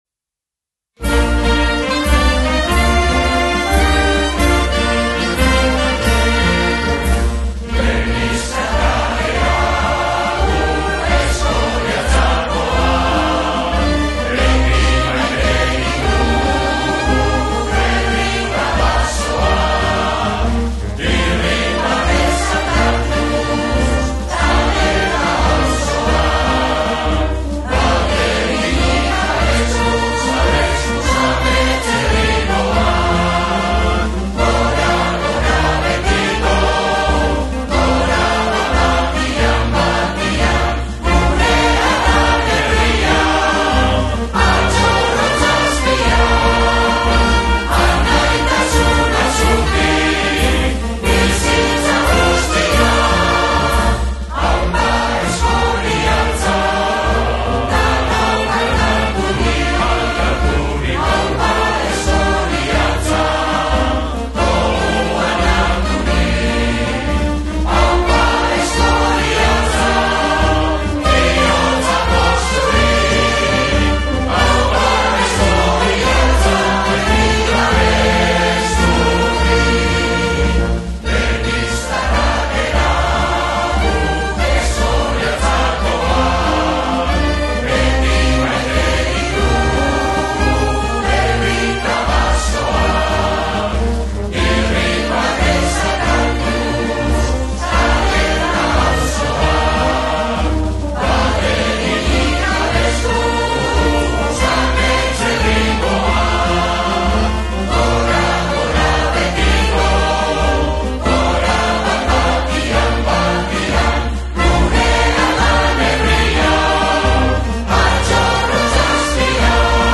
Orain, 37 urte beranduago, teknologia berriak aprobetxatuz grabazio berria egin da, musika eta ahotsarekin, eta bihar, ekainaren 28a, zuzenean entzun ahal izango dugu txupinazoaren ostean.
Beheko Errota Musika Eskolak sustatuta asmo honetan, 49 lagunek parte-hartu dute, hiru ahotsetan abestuz, eta Musika Eskolako bandak ere parte hartu du.